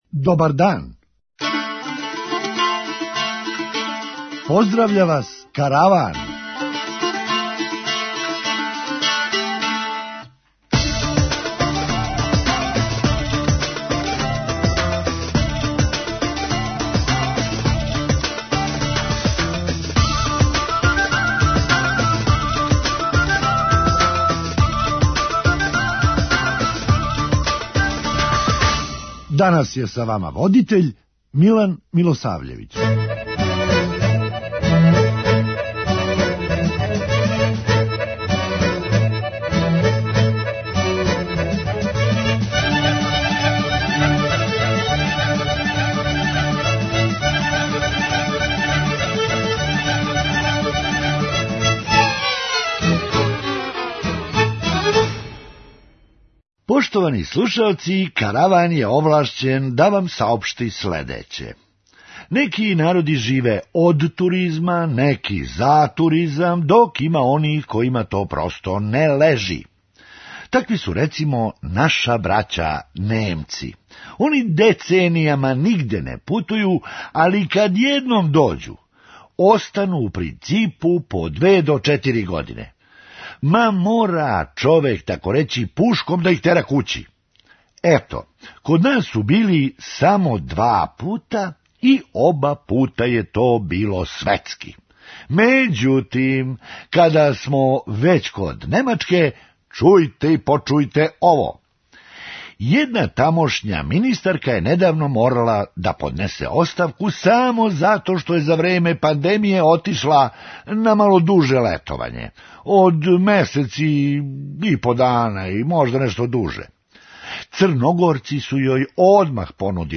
Хумористичка емисија
Сирота наша деца, шта све може да им се обије о главу. преузми : 9.03 MB Караван Autor: Забавна редакција Радио Бeограда 1 Караван се креће ка својој дестинацији већ више од 50 година, увек добро натоварен актуелним хумором и изворним народним песмама.